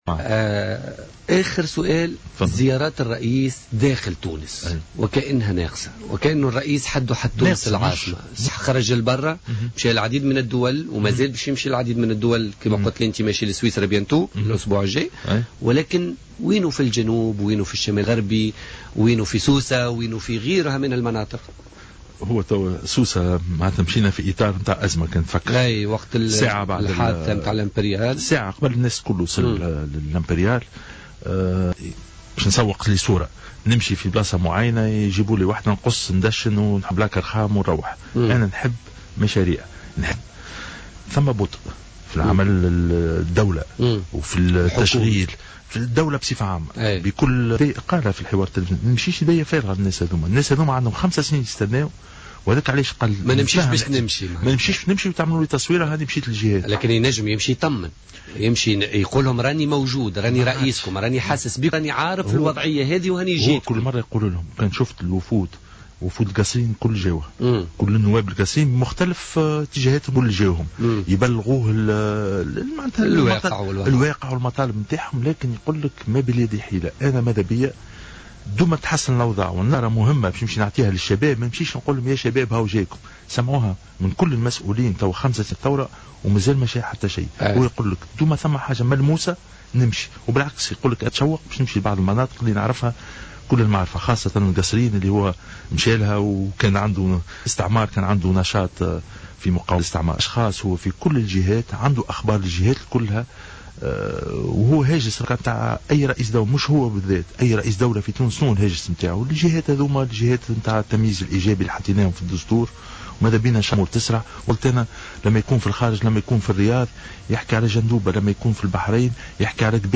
قال الناطق الرسمي باسم رئاسة الجمهورية معز السيناوي ضيف برنامج بوليتكا لليوم الثلاثاء 09 فيفري 2016 إن زيارات رئيس الجمهورية للمناطق الداخلية تعد فعلا منقوصة مؤكدا في المقابل أنه لها أسبابها.